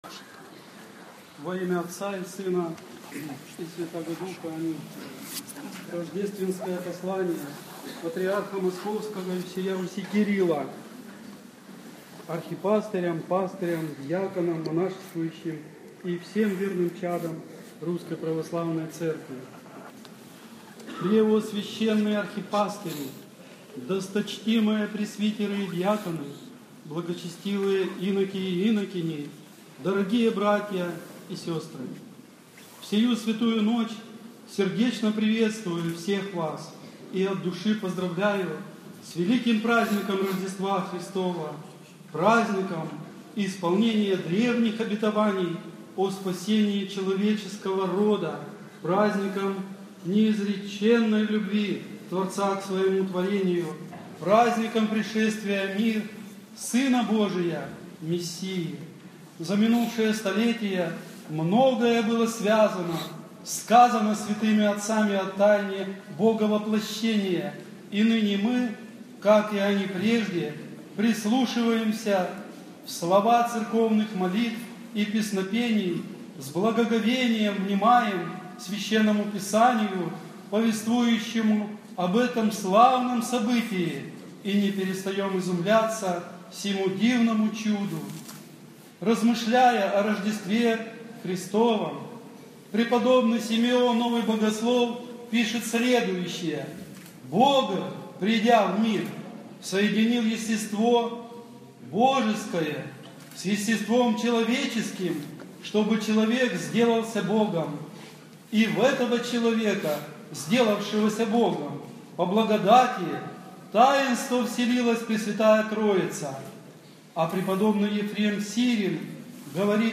Ночная Божественная Литургия в нашем храме в праздник Рождества Христова